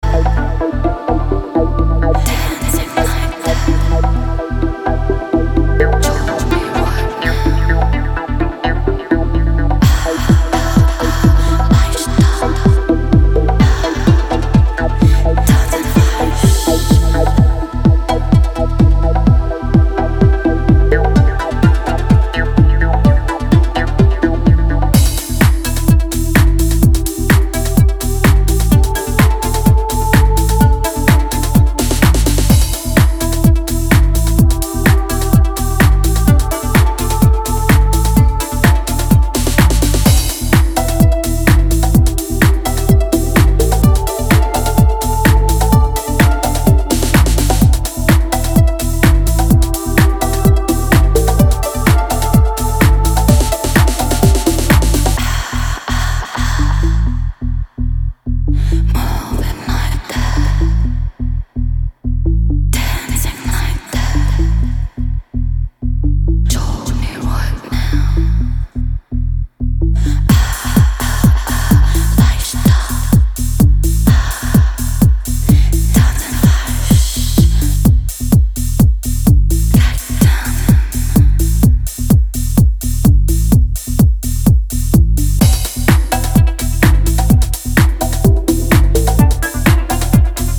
soft and contrasting vocals